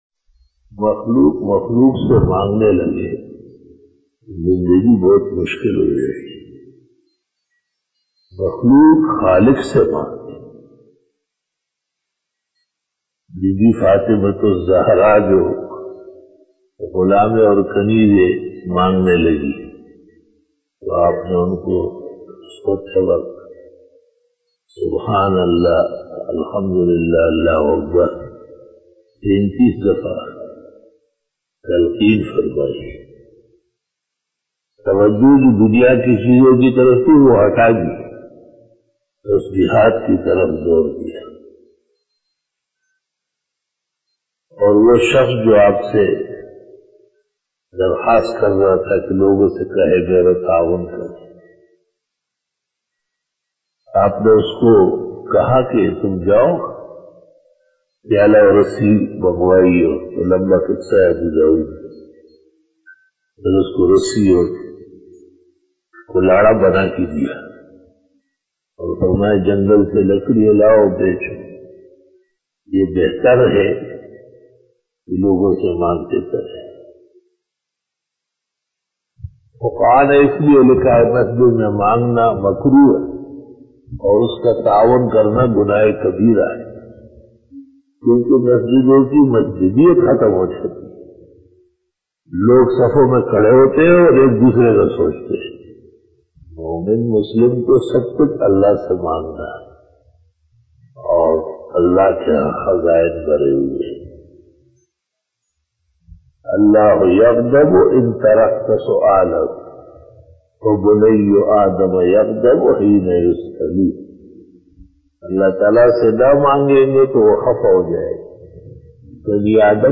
After Asar Byan